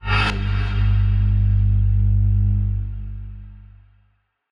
shield-spell-v1-intro-03.ogg